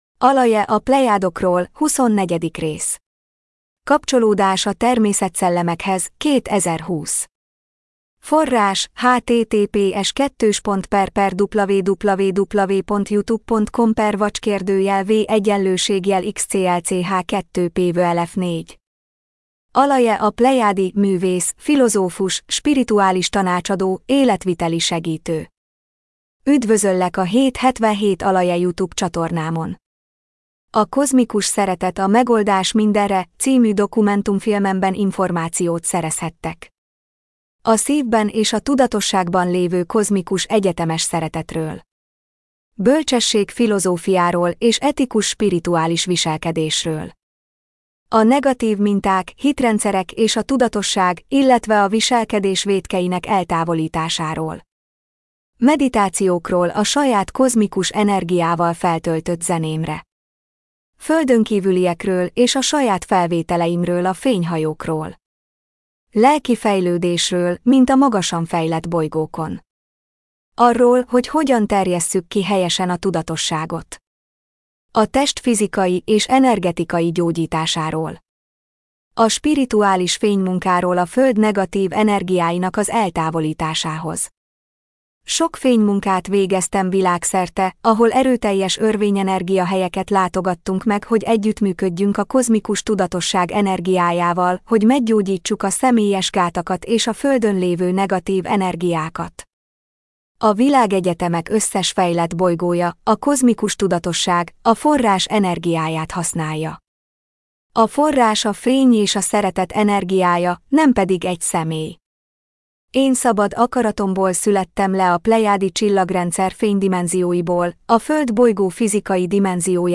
MP3 gépi felolvasás